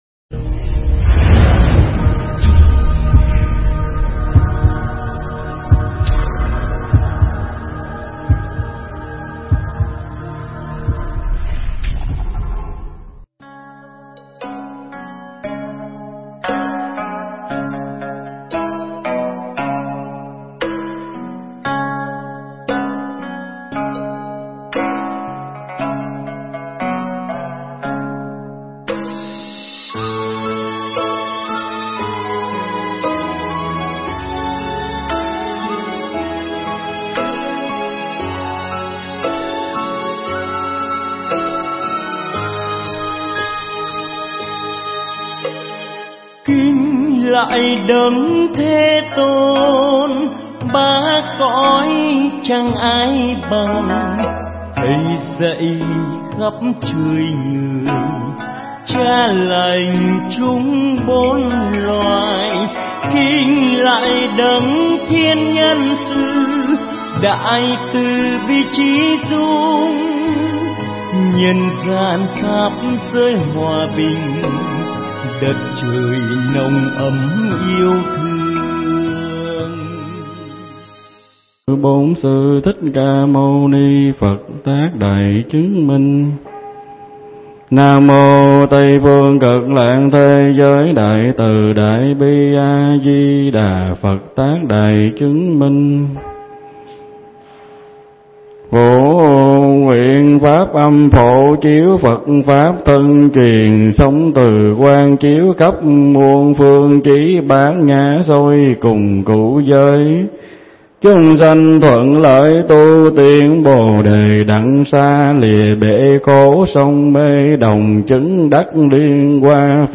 Nghe Mp3 thuyết pháp Tu Tạo Công Đức Phước Báu